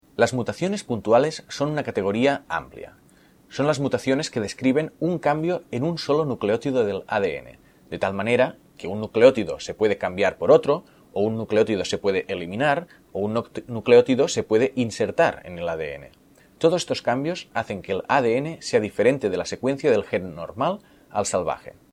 Recurso de narración: